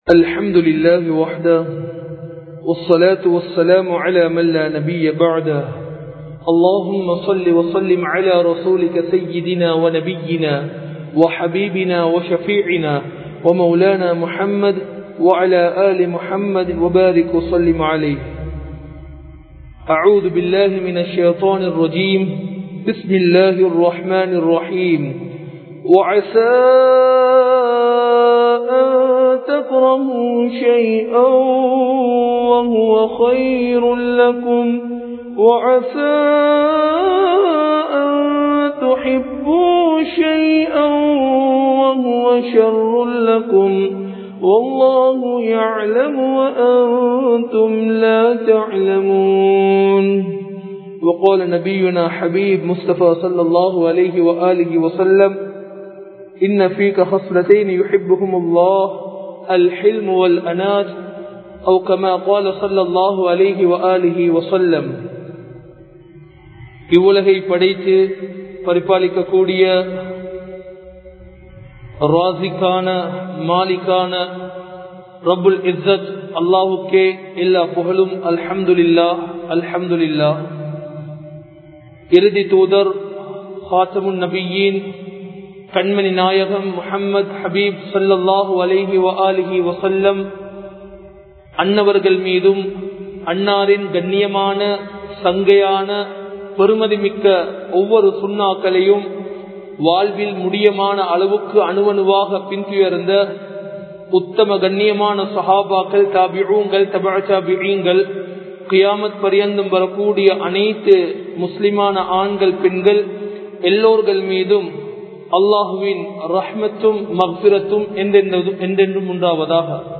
இறை இல்லங்களை வெறுக்காதீர்கள் (Don't Hate the house of allah) | Audio Bayans | All Ceylon Muslim Youth Community | Addalaichenai
Majma Ul Khairah Jumua Masjith (Nimal Road)